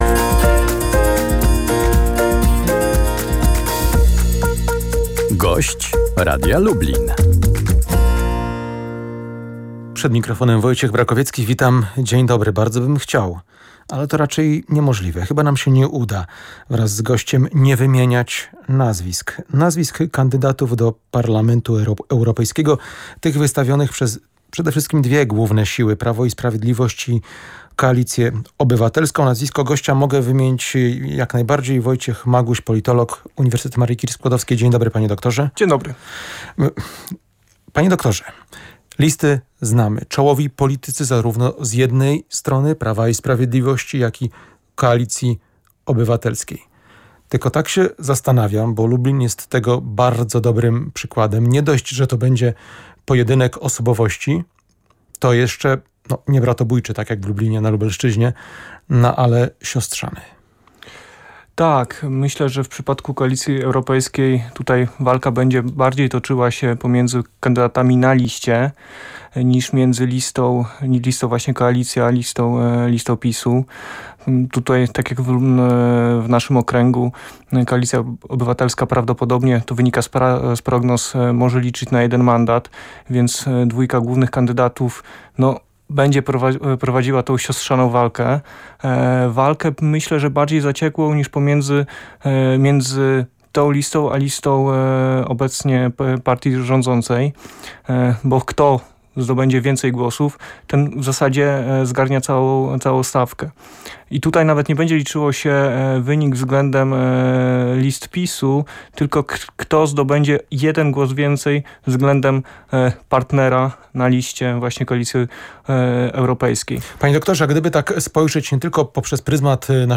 gość porannej rozmowy w Radiu Lublin